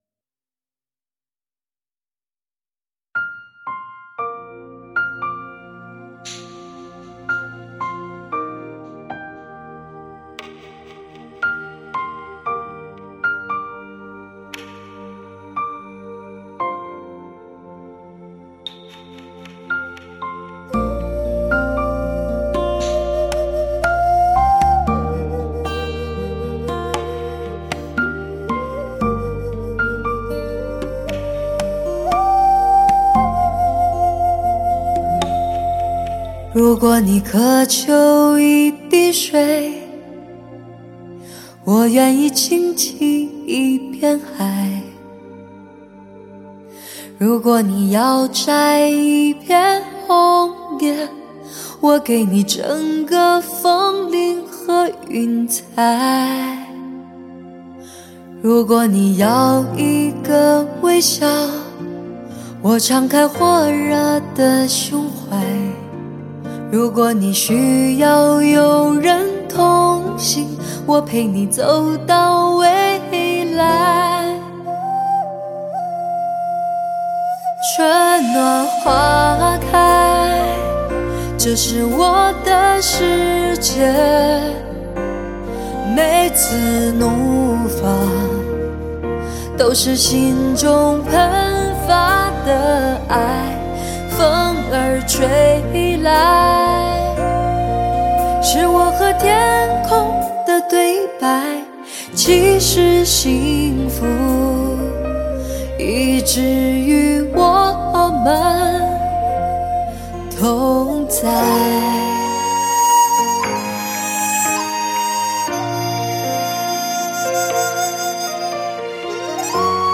360°极致环绕
最具诱惑力的发烧音色，让你感受超完全无法抵御的声色魅力！
顶级最具视听效果的发烧原音，试机宝贝中的典范